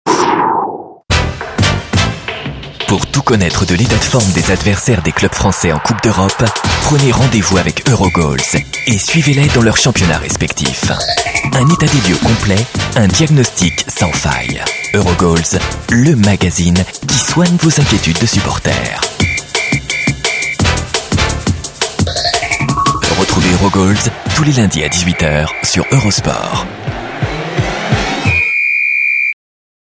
EUROSPORT foot - Comédien voix off
Genre : voix off.